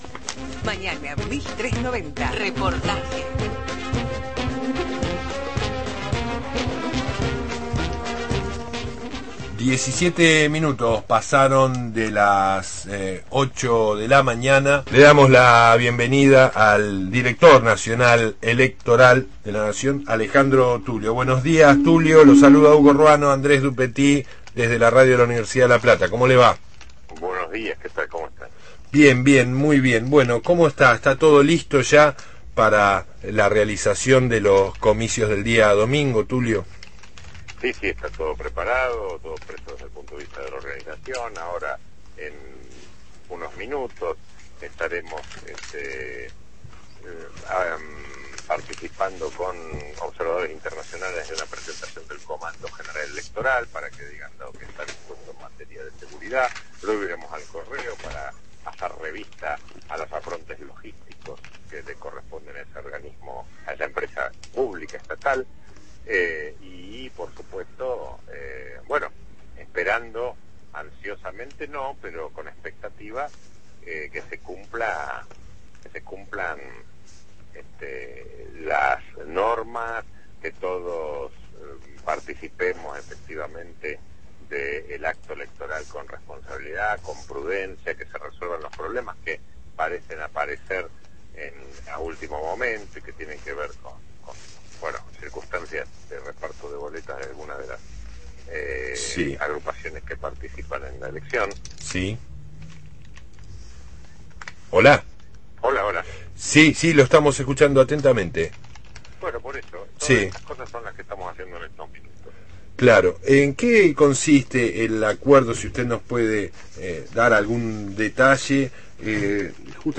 Alejandro Tullio, Director Nacional Electoral, dialogó